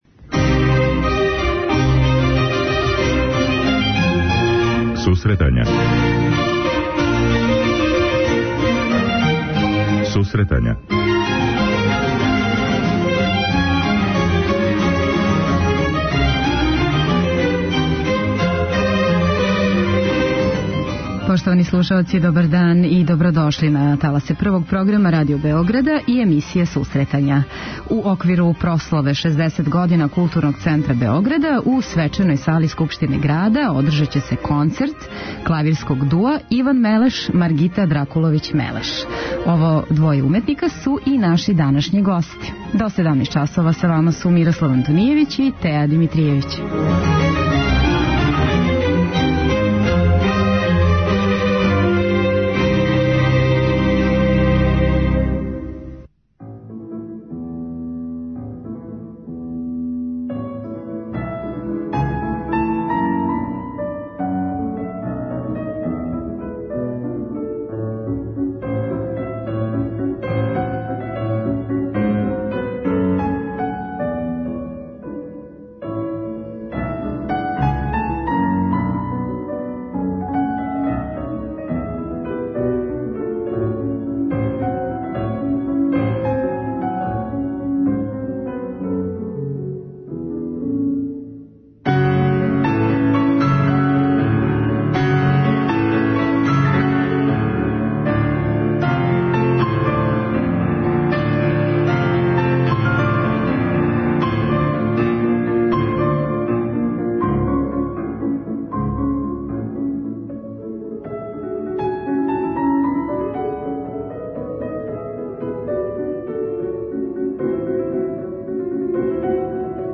Ово двоје уметника су наши данашњи гости.